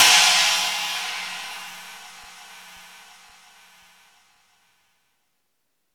CYM R8 CHI0X.wav